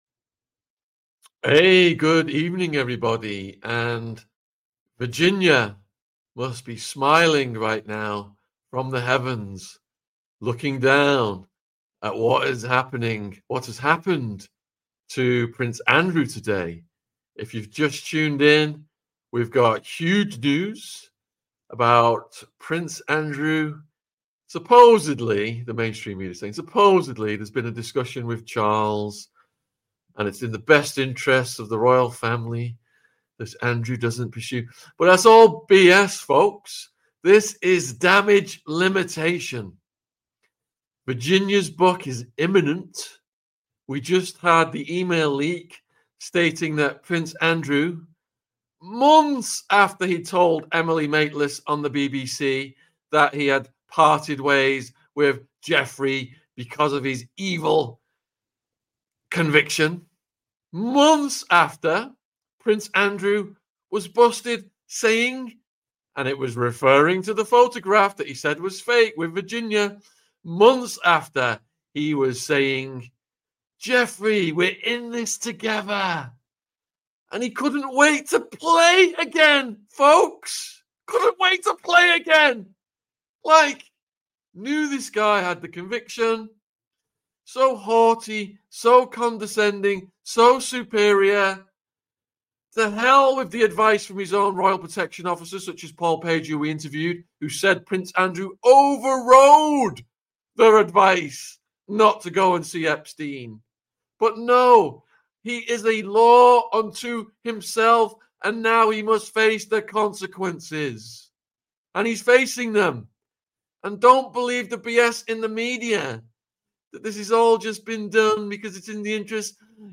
King Charles STRIPS Prince Andrew's Royal Titles - Call in Show - Royal Family Fergie Virginia | AU 491